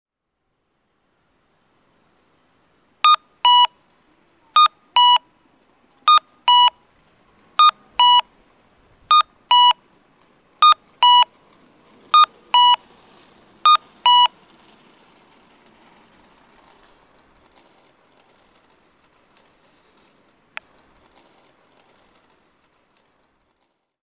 鶴崎駅前(大分県大分市)の音響信号を紹介しています。